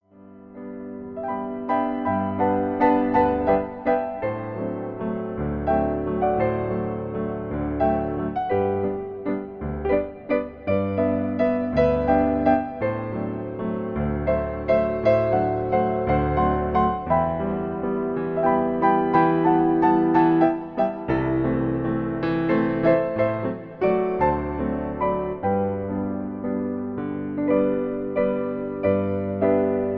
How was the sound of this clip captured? made for a viennese recital